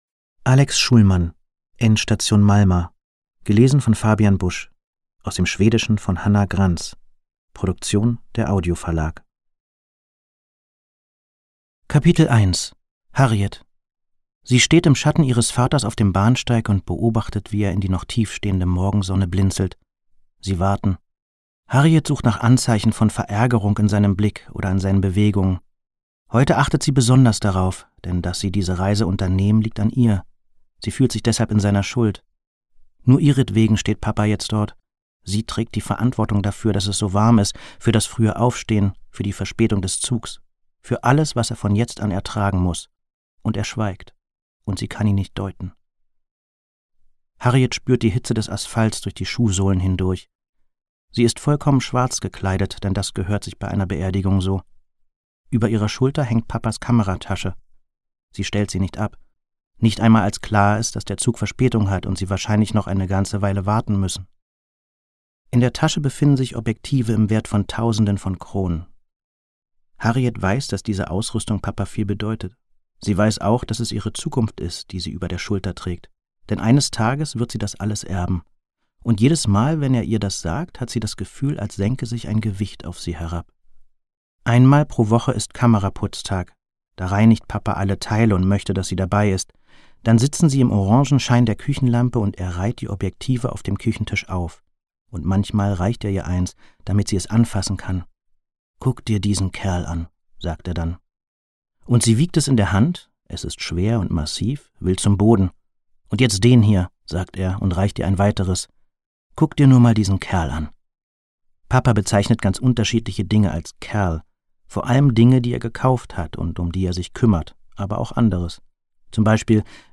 Ungekürzte Lesung mit Fabian Busch (1 mp3-CD)
Fabian Busch (Sprecher)